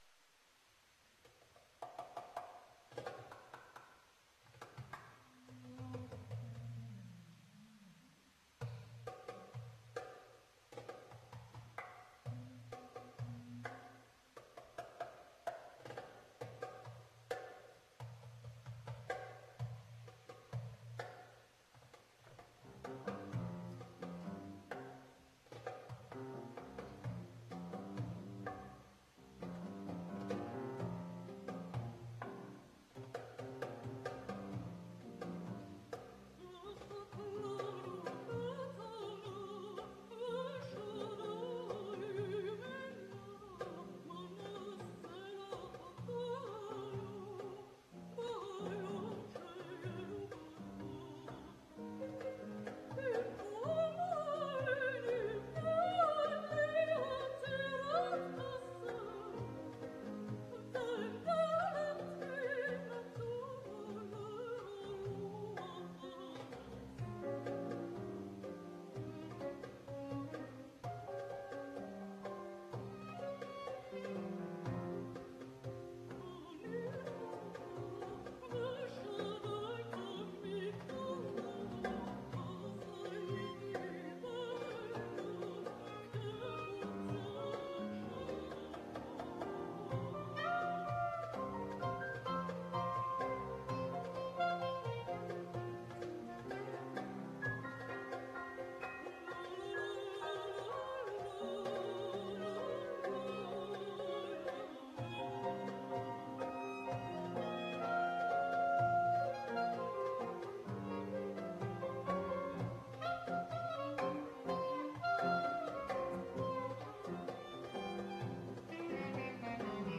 Le dimanche 3 novembre 2002 à 17h, Conservatoire de Musique de Genève - Place-Neuve
Chant & ensemble